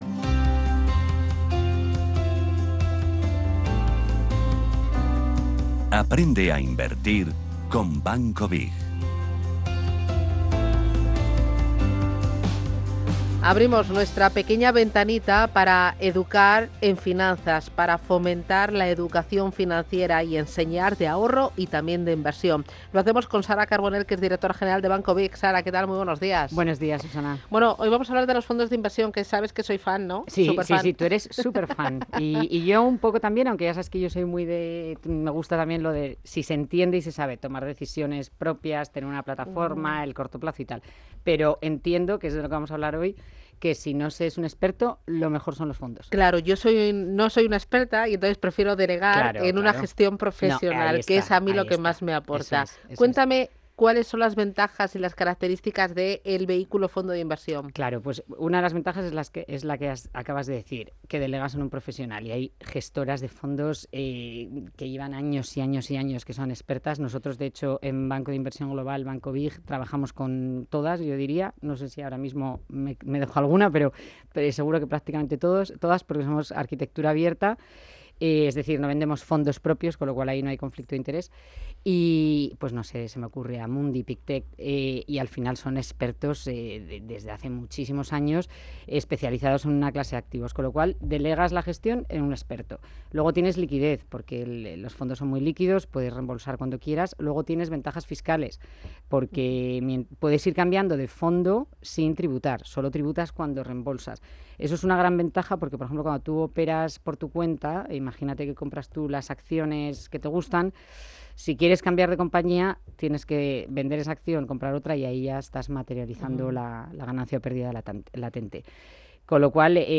Sección "Aprende a Invertir con BiG" junto a Radio Intereconomía